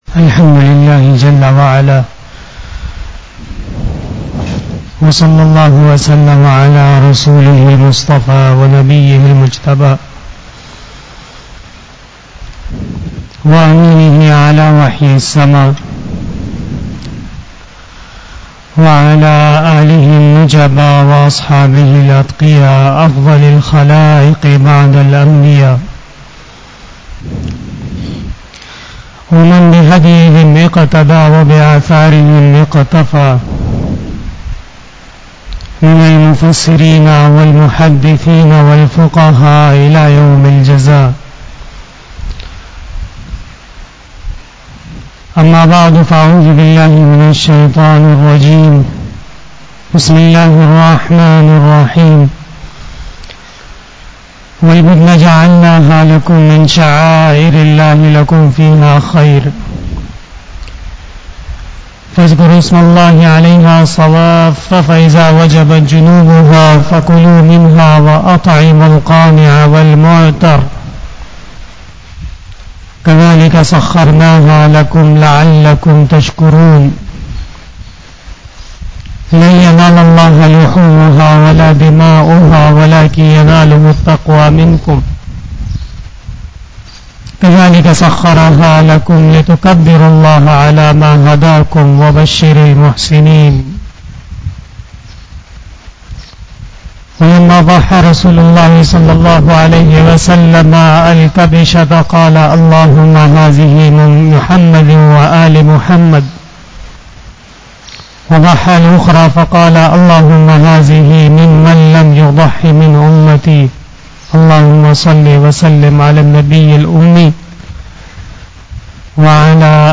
26 BAYAN E JUMA TUL MUBARAK 25 June 2021 (14 Zil Qadah 1442H)
02:20 PM 514 Khitab-e-Jummah 2021 --